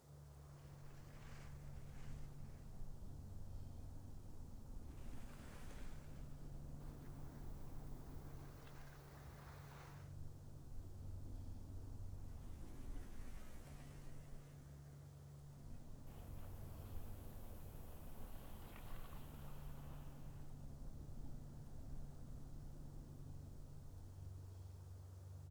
amb_skiing.wav